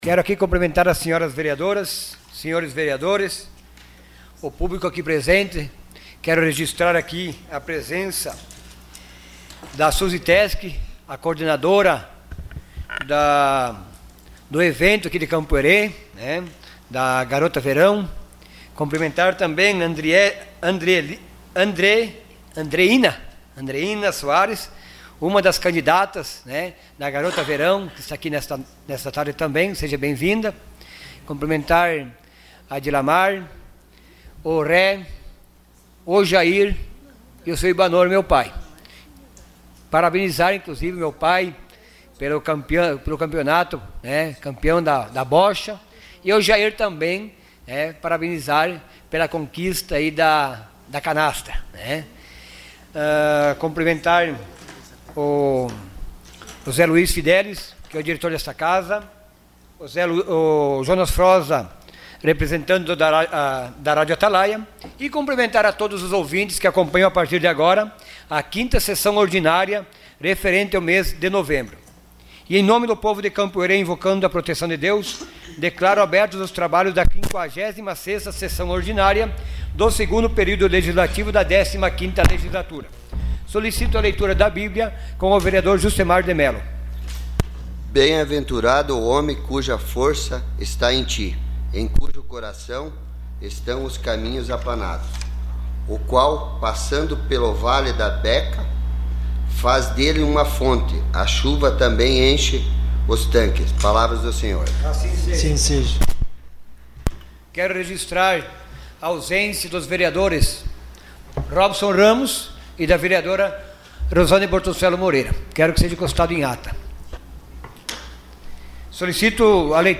Sessão Ordinária dia 21 de novembro de 2018.